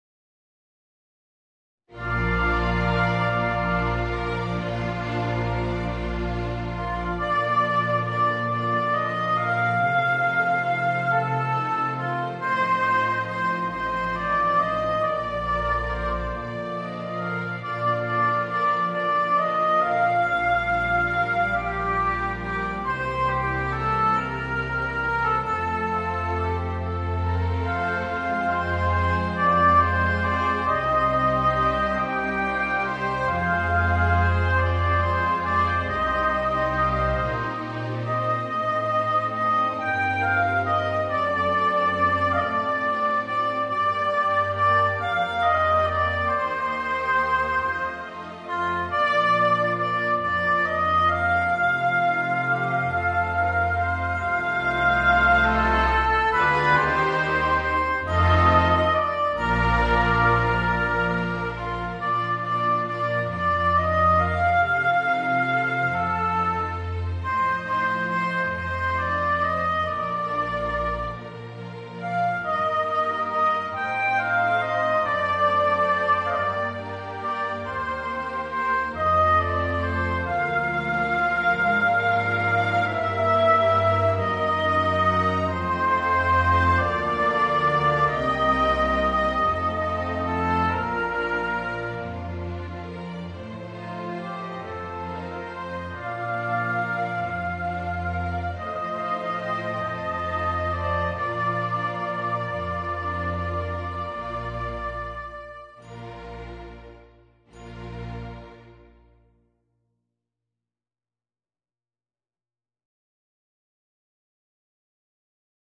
Voicing: Viola and Orchestra